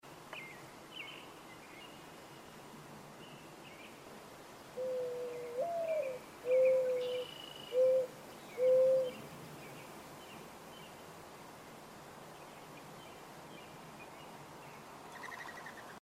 That nostalgic mourning dove sound